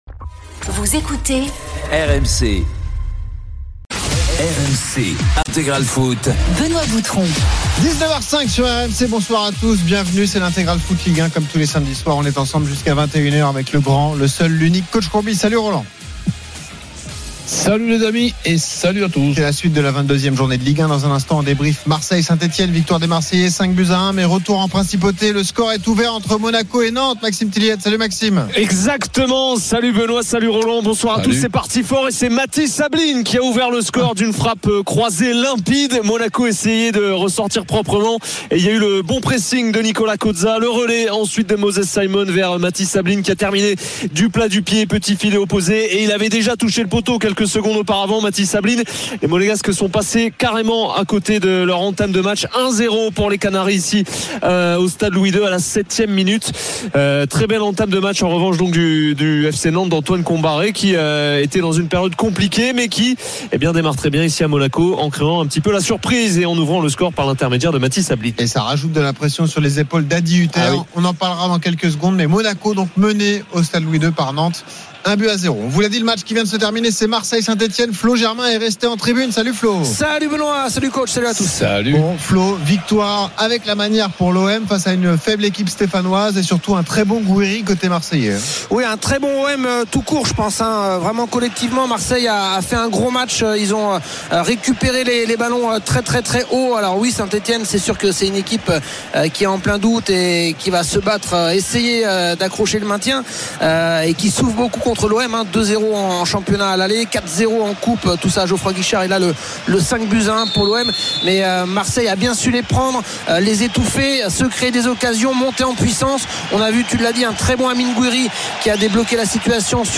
Le rendez vous Ligue 2 de RMC. Huit matches par journée à suivre en direct et des acteurs du championnat (joueurs, entraîneurs, présidents) invités pendant deux heures.